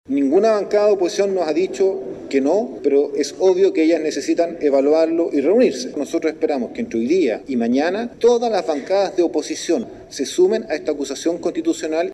El diputado del PC, Daniel Núñez, dijo esperar un apoyo transversal de la oposición para llevar a cabo un juicio político contra Ossa.